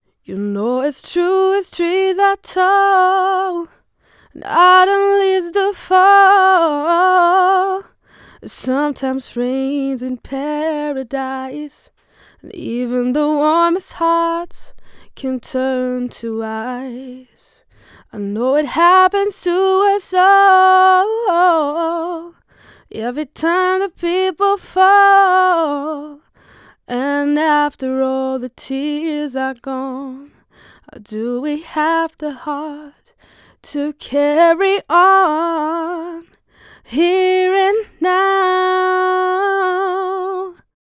Dieser ist sehr warm. Die Stimmen sind etwas angedickt.
Der Sprechersound ist klar und voll. Die Höhen sind präsent, aber gut abgestimmt.
Das Rauschen des Mikrofons ist insgesamt wirklich gering und auch die Spinne macht eine gute Arbeit.
Gesangstests